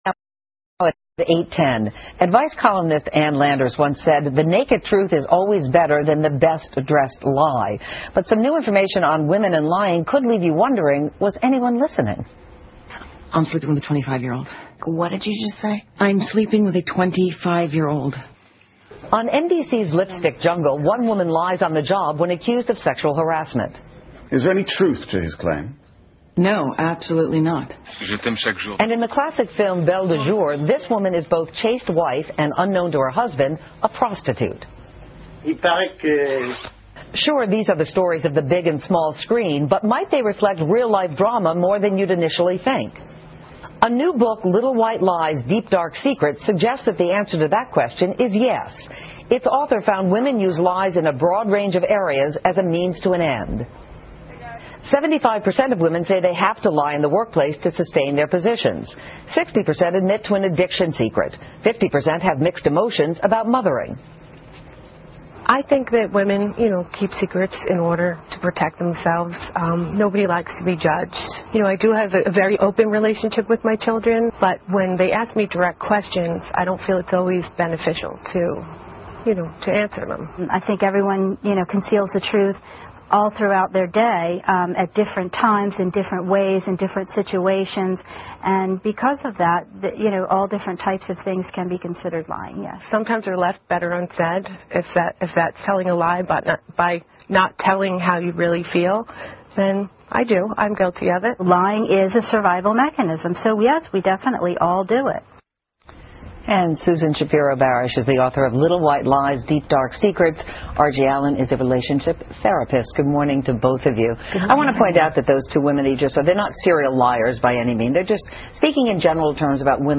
访谈录 2008-03-07&-3-09, 女人为什么会撒谎 I 听力文件下载—在线英语听力室